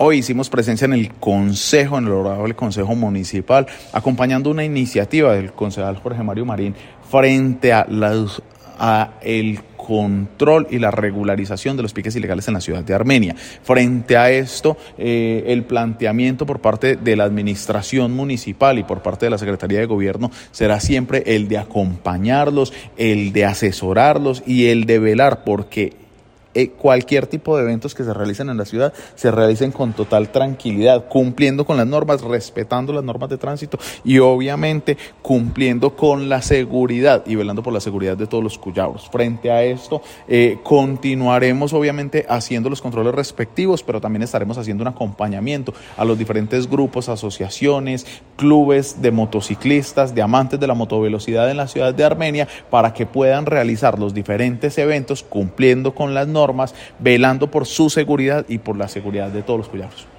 La Administración Municipal acompañó la sesión que se llevó a cabo en el Concejo Municipal, mediante proposición realizada por el concejal Jorge Mario Marín Buitrago, en la que se abordó el tema de los piques ilegales.
Audio: Jorge Andrés Buitrago Moncaleano, secretario de Gobierno:
Audio_Secretario_de_Gobierno_Jorge_Andres_Buitrago_Moncaleano.mp3